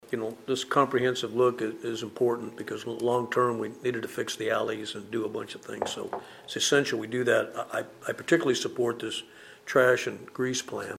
Mayor Wynn Butler says it’s a good plan.